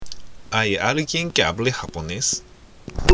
（アイ　アルギエン　ケアブレ　ハポネス？）